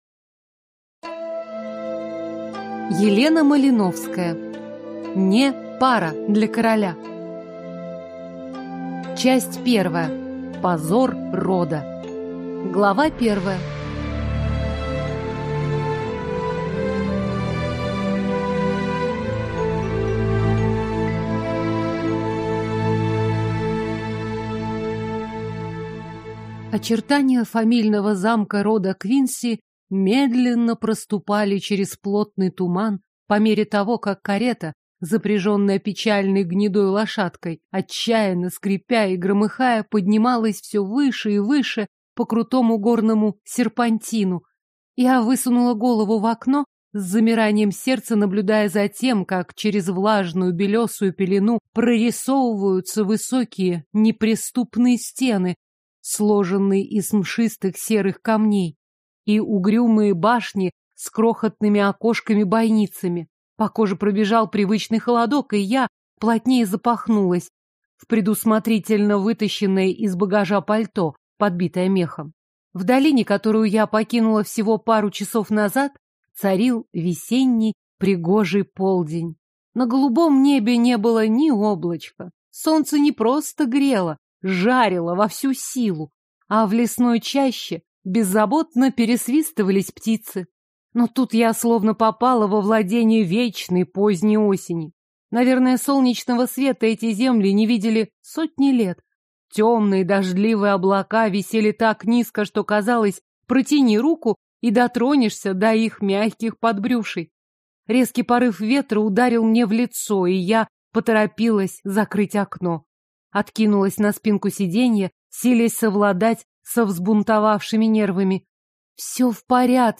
Аудиокнига (Не) пара для короля | Библиотека аудиокниг